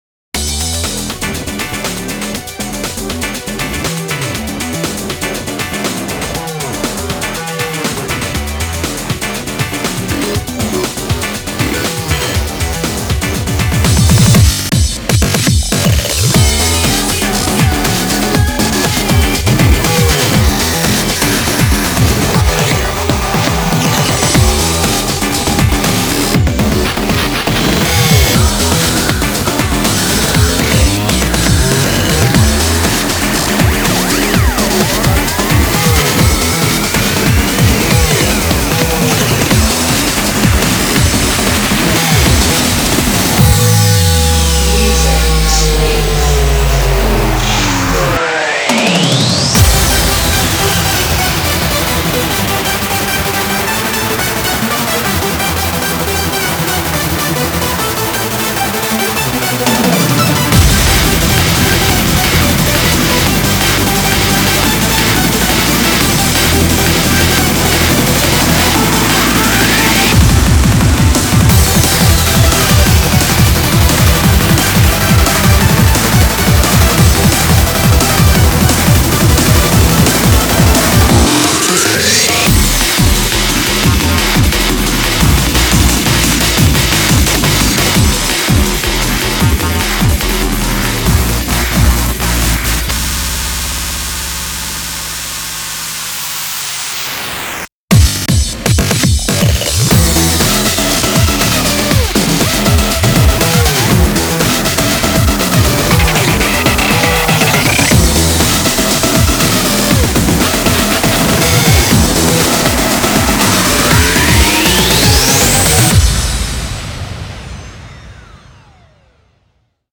BPM50-175